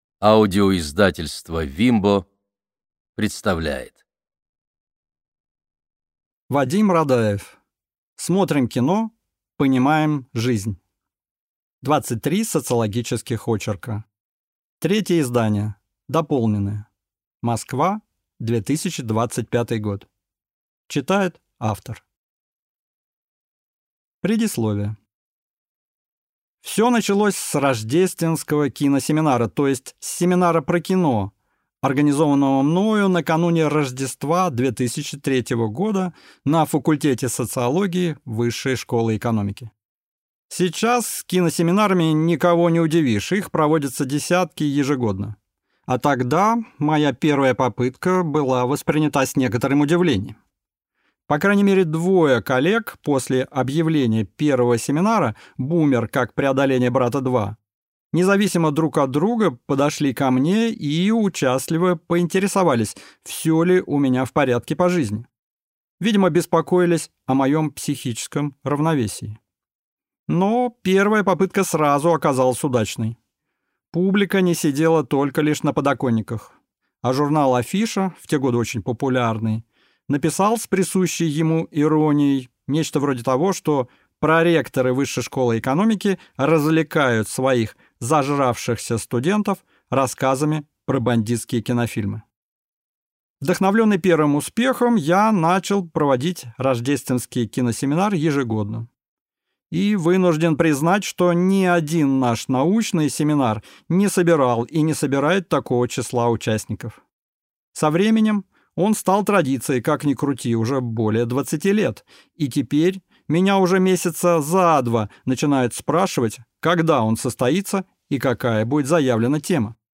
Аудиокнига Смотрим кино, понимаем жизнь: 19 социологических очерков | Библиотека аудиокниг
Aудиокнига Смотрим кино, понимаем жизнь: 19 социологических очерков Автор В. В. Радаев Читает аудиокнигу В. В. Радаев.